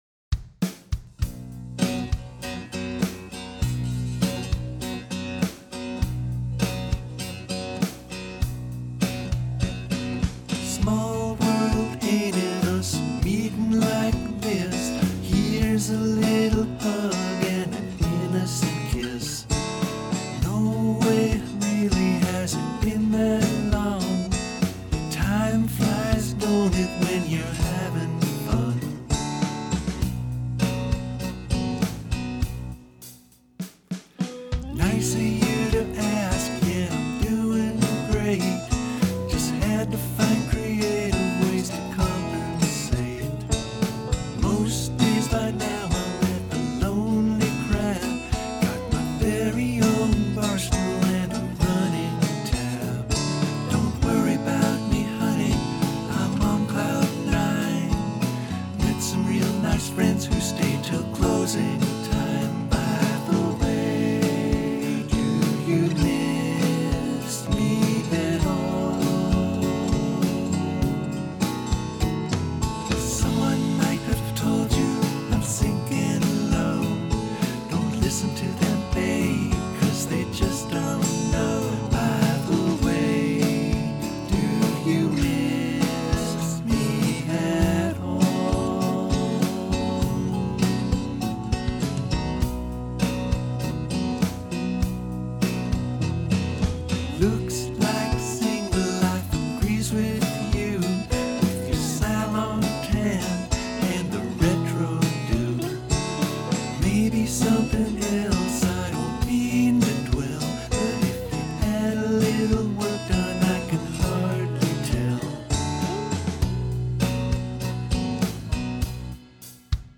This song is the result of my learning about a very strange guitar tuning -- one that as far as I know, has only ever been used by Joni Mitchell.
The tuning is Dropped B - F# - B - E - A - E. See, I told you this was for nerds